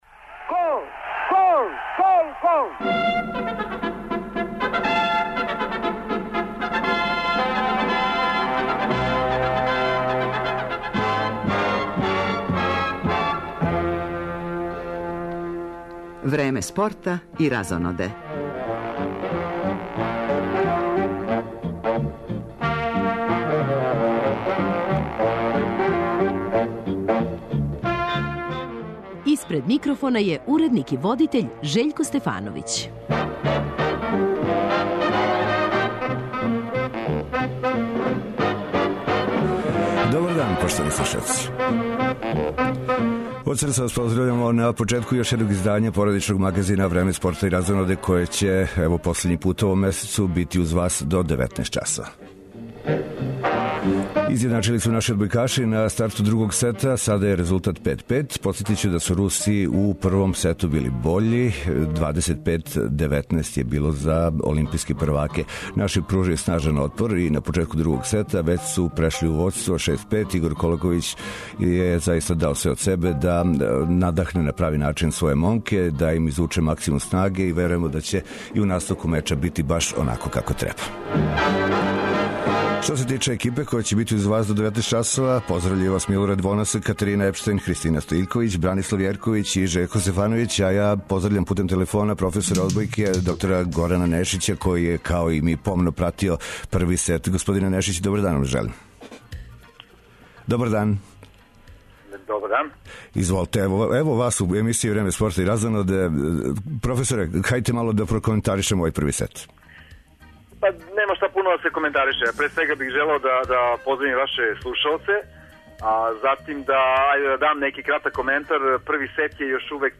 Одбојкаши Србије крећу у одлучне битке за одбрану титуле европског првака - њихов ривал у полуфиналном мечу је селекција Русије. Овај меч пратићемо током емисије, уз коментаре наших познатих тренера.